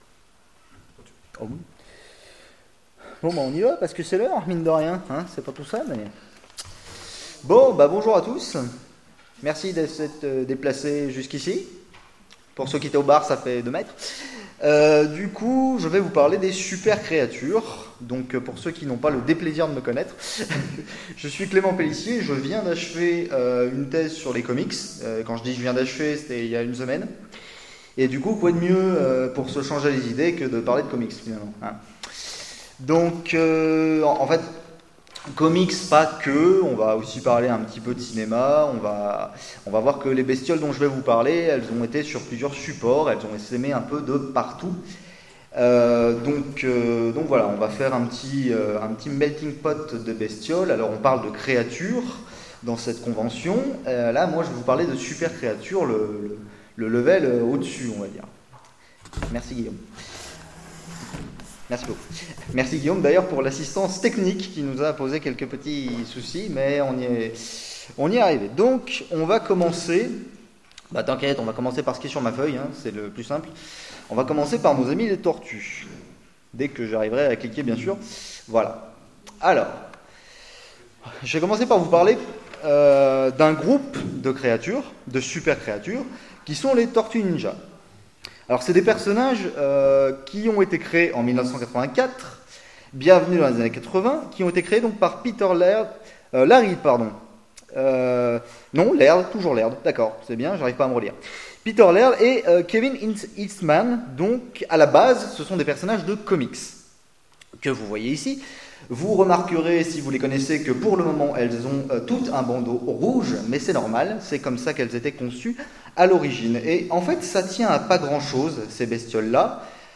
Convention SF 2017 : Conférence Super Pouvoirs, Super Créatures
Convention_sf_2017_conference_Super_Pouvoir_Super_Creatures_ok.mp3